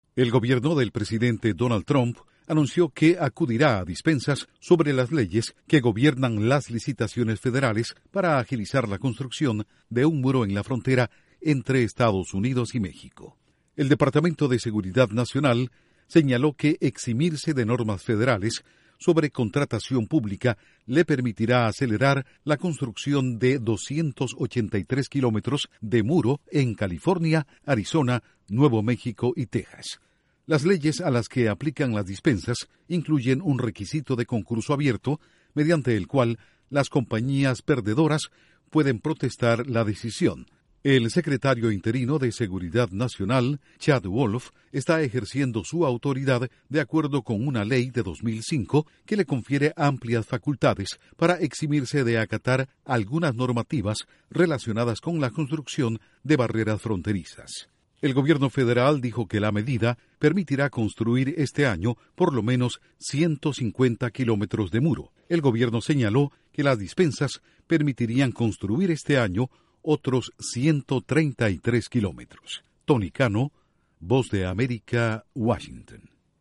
Estados Unidos recurre a dispensas para acelerar construcción del muro fronterizo con México. Informa desde la Voz de América en Washington